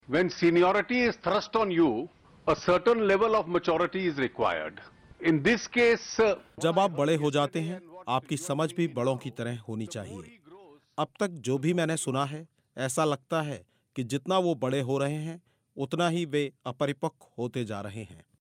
अरुण जेटली का राहुल गांधी पर बयान. कहा 'बड़े होने पर समझ भी बड़ों की तरह होनी चाहिए'